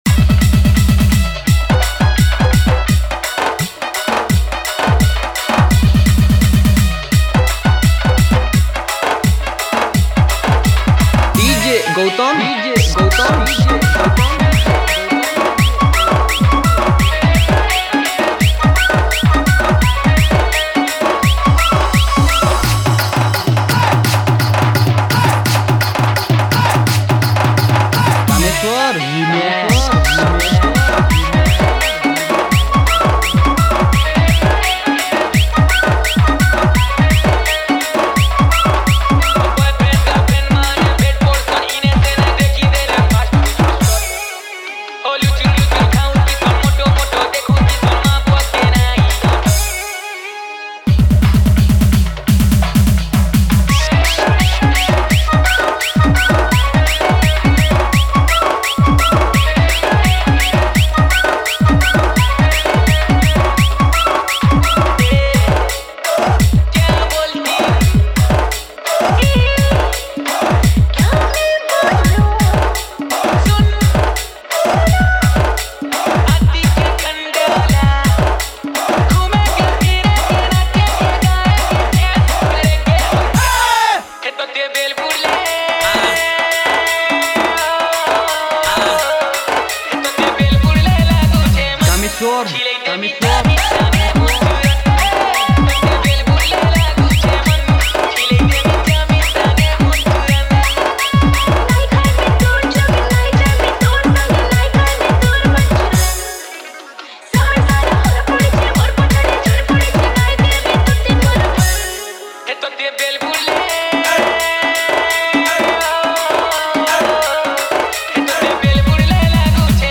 Sambalpuri Dj Song 2024
Category:  Sambalpuri Dj Song 2022